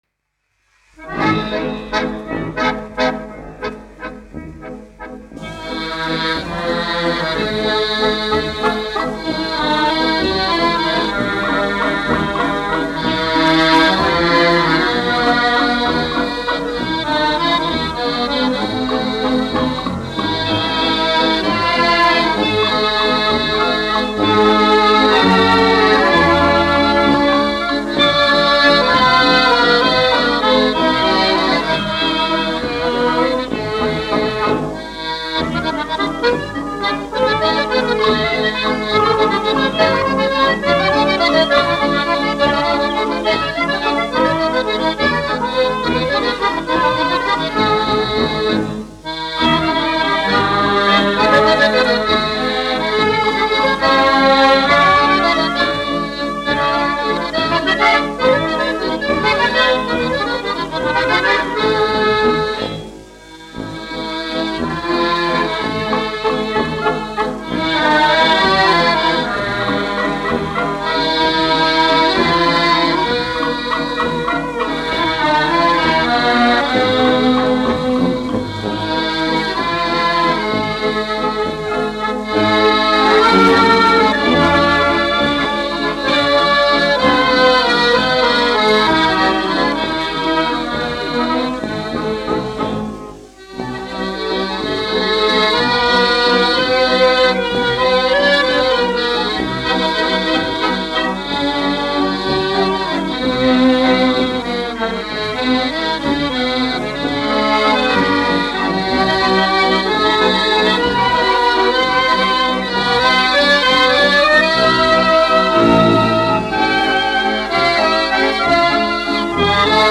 1 skpl. : analogs, 78 apgr/min, mono ; 25 cm
Deju orķestra mūzika
Skaņuplate